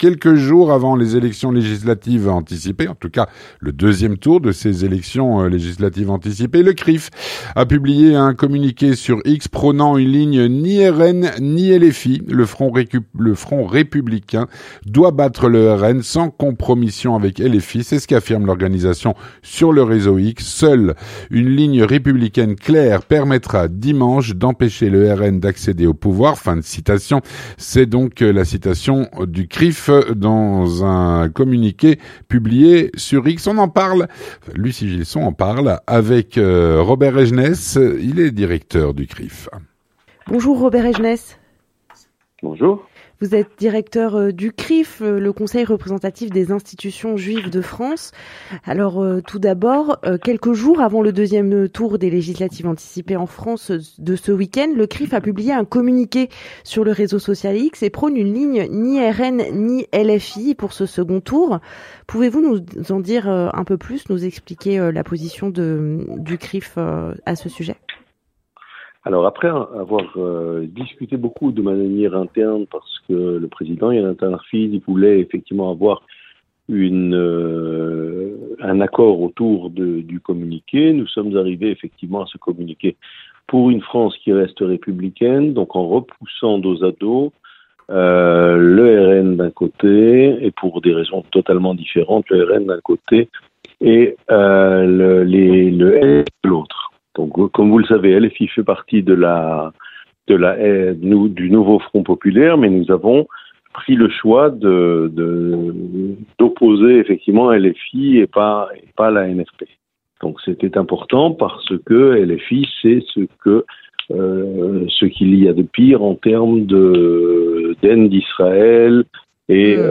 L'entretien du 18H - Avant le 2ème tour des élections législatives en France, le CRIF a publié un communiqué sur X prônant une ligne « ni RN ni LFI ».